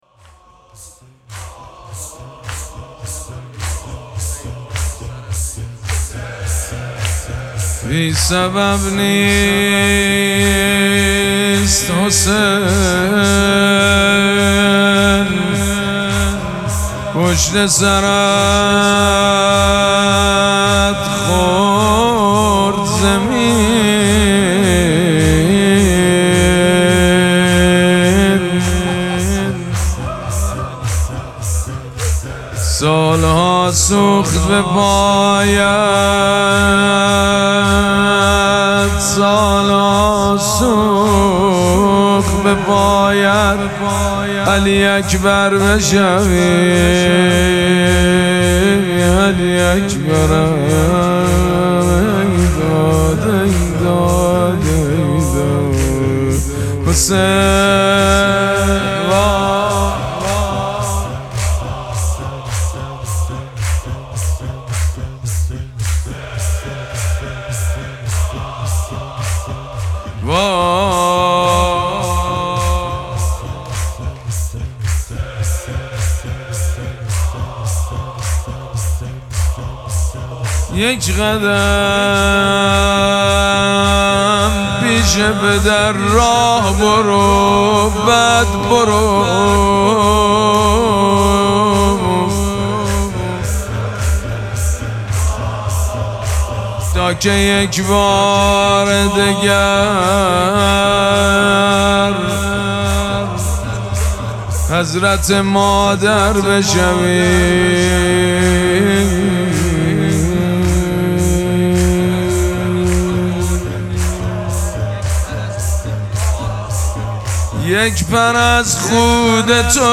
مراسم مناجات شب بیستم ماه مبارک رمضان
مداح
حاج سید مجید بنی فاطمه